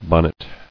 [bon·net]